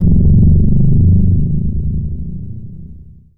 timwwarp kick.wav